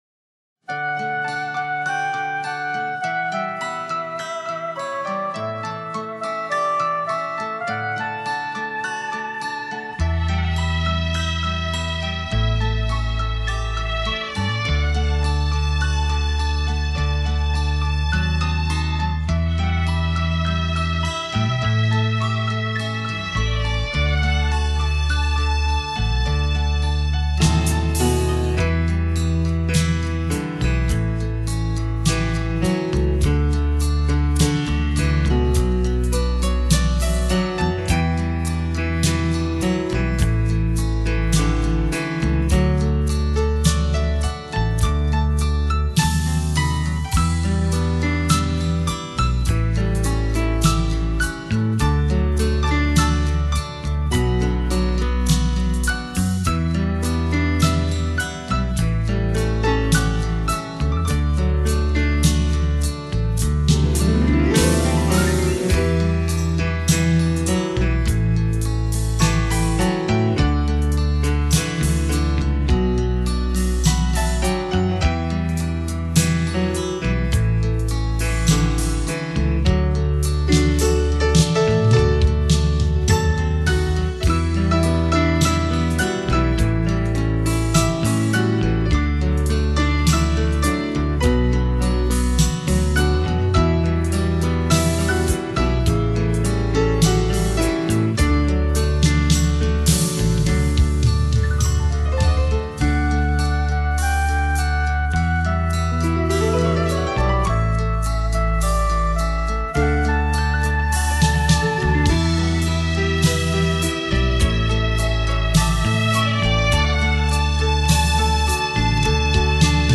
piano,钢琴